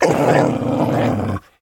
bdog_hurt_3.ogg